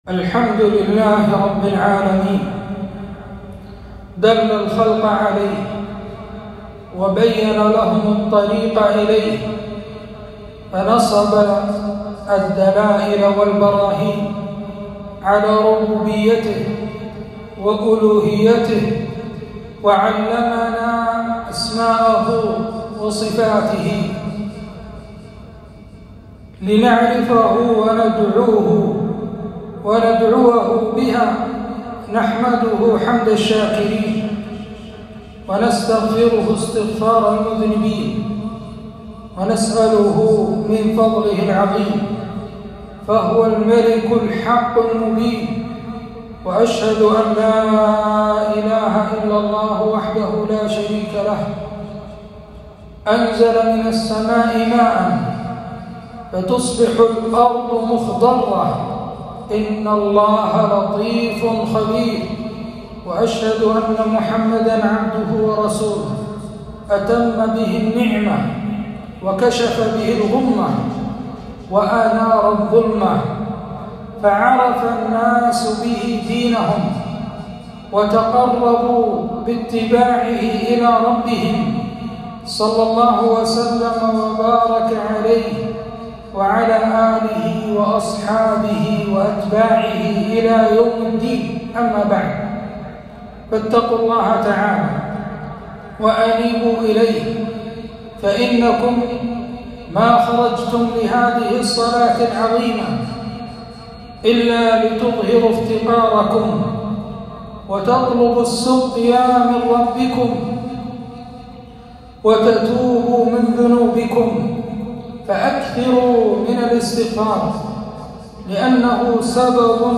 خطبة الاستسقاء - الافتقار إلى الله